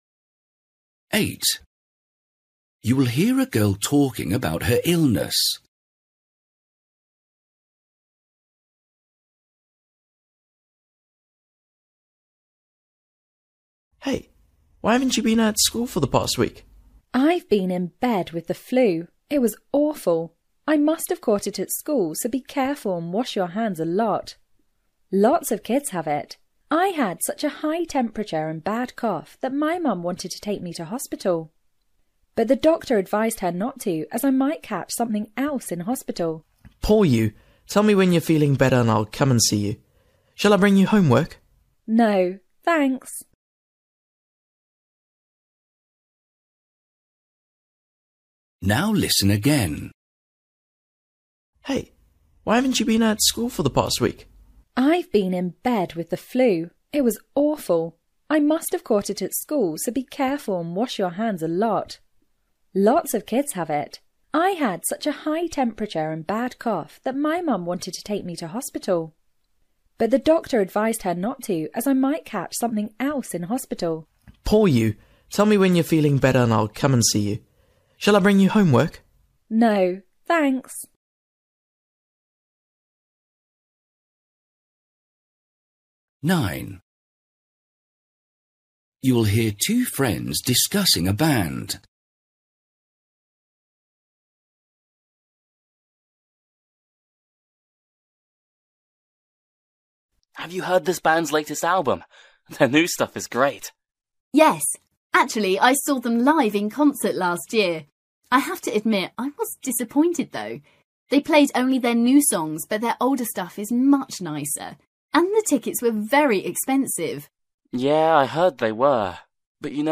Listening: everyday short conversations
8   You will hear a girl talking about her illness. The girl advises the boy to
9   You will hear two friends discussing a band. They agree that the band